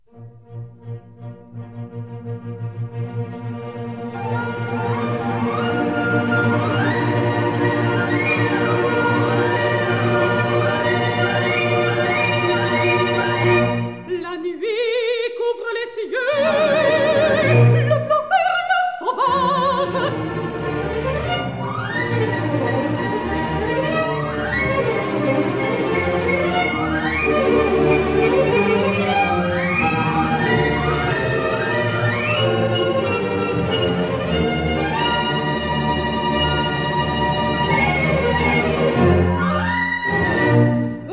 soprano
harpsichord
Stereo recording made in April 1962